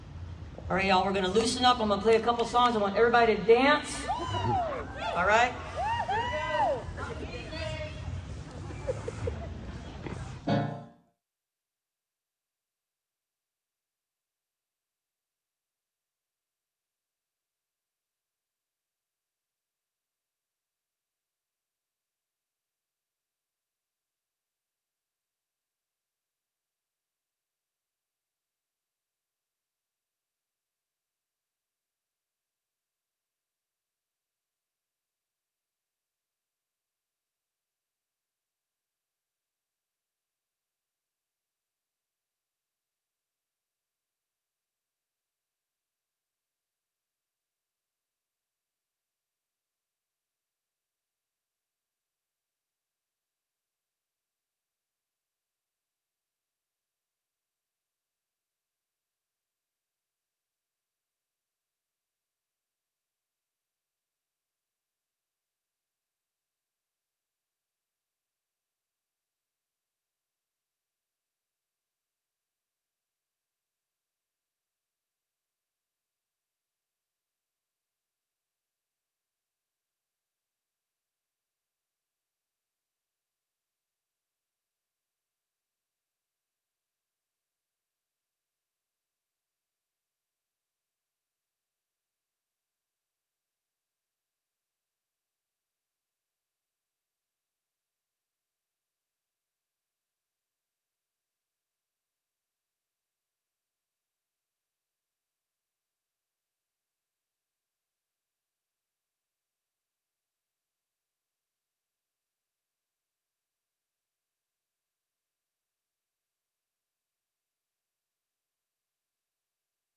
(captured from a facebook livestream)
04. amy ray introduces an unknown song (blanked out in the livestream) (2:23)